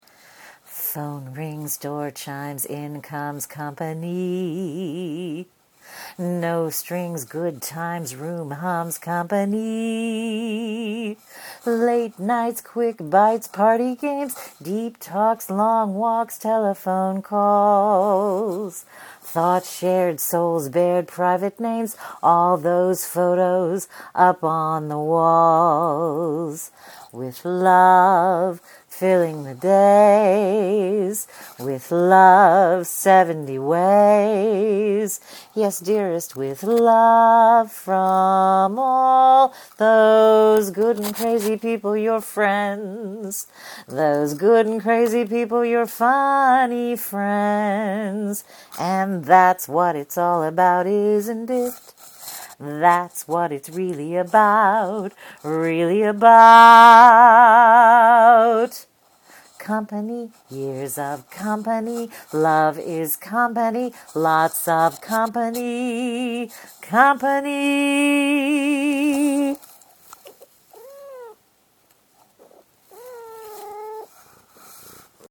The bold lines in this post are a song